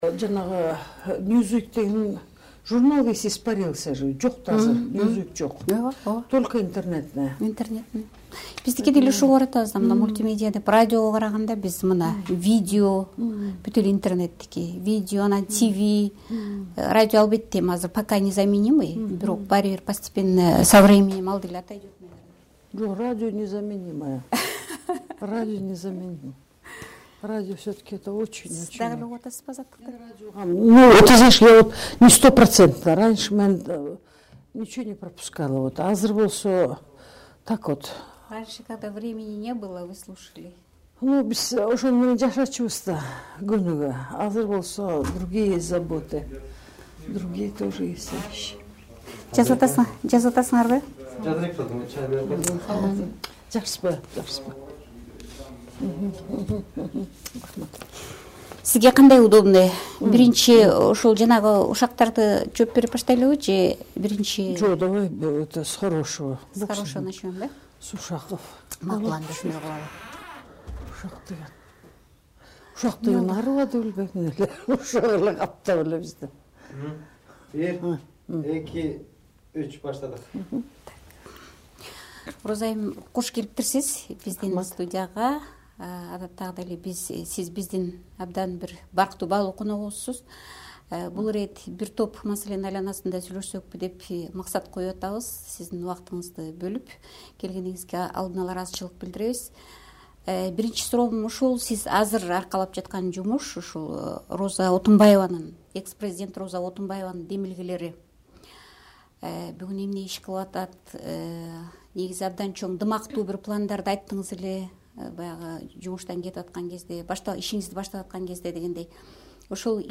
Роза Отунбаева менен маектин 1-бөлүгү